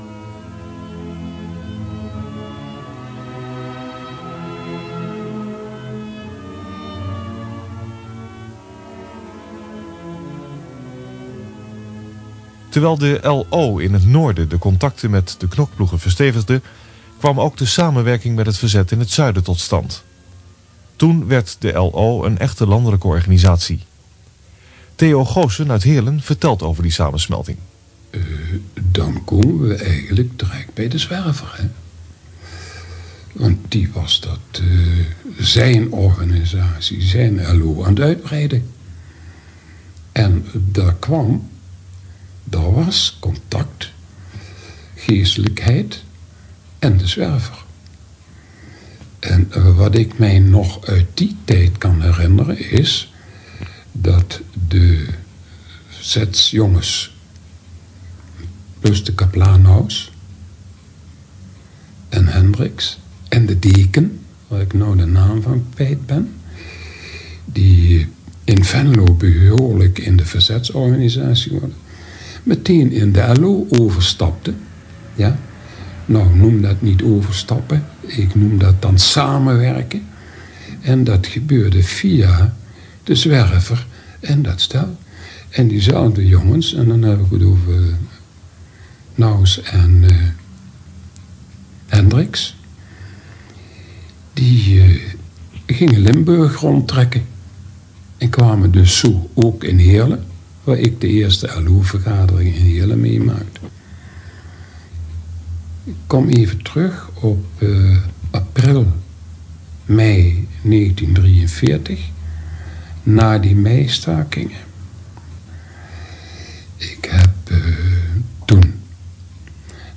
Geluidsfragmenten over de LO in het zuiden van ons land